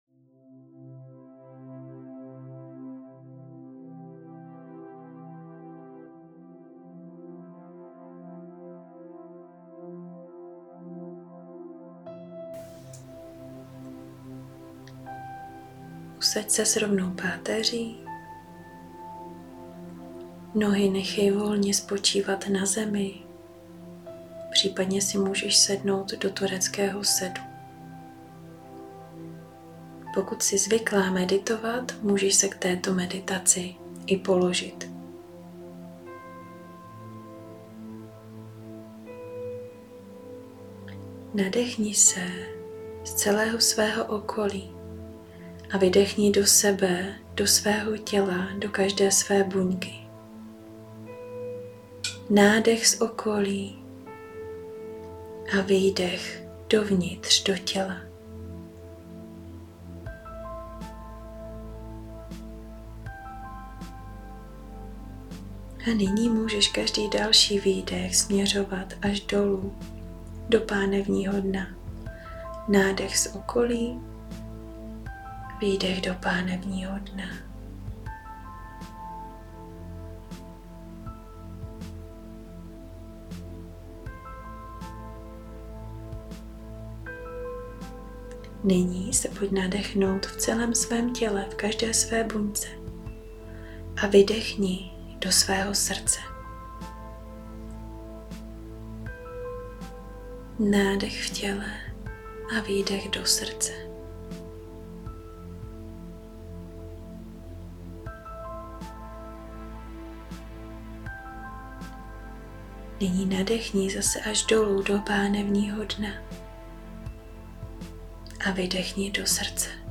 Vedená meditace SPOJENÍ S PÁNEVNÍM DNEM A JEHO DARY Pohodlně se usaď, raději si nelehej, chodila dej na zem, nebo do zkříženého sedu.
Meditace-spojeni-s-panevnim-dnem-V3.mp3